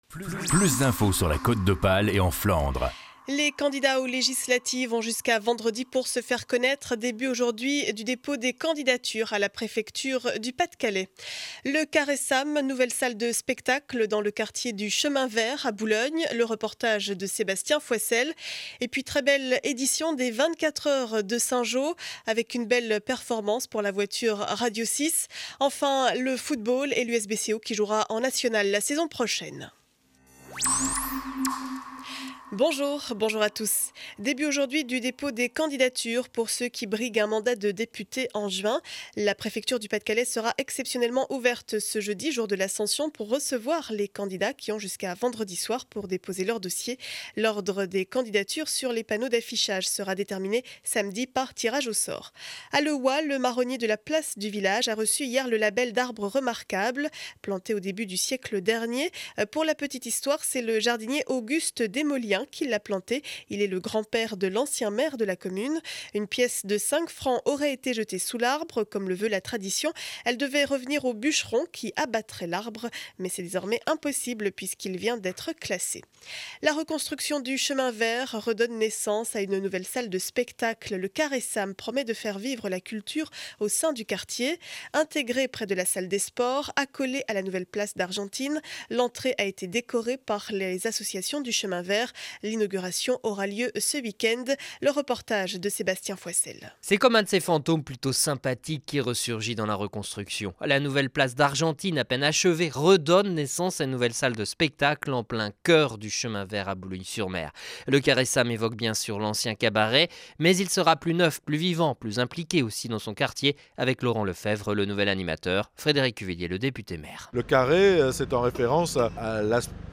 Journal du lundi 14 mai 2012 7 heures 30, édition du Boulonnais